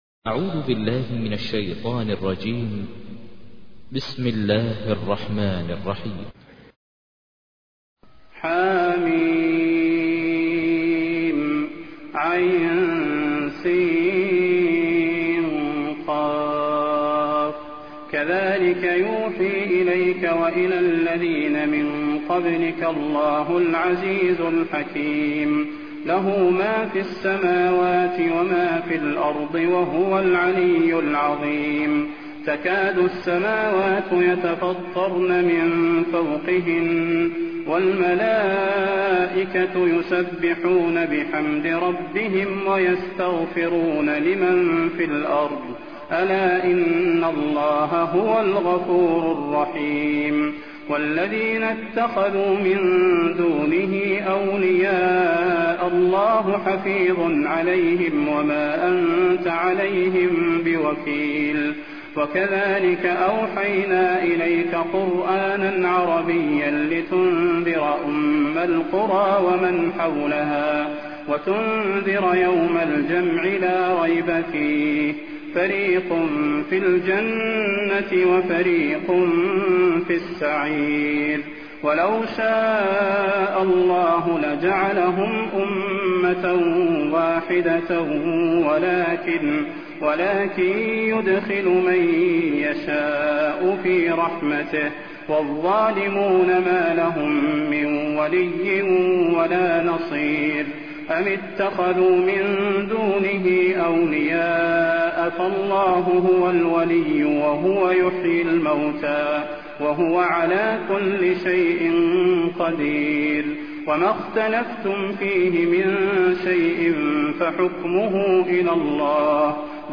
تحميل : 42. سورة الشورى / القارئ ماهر المعيقلي / القرآن الكريم / موقع يا حسين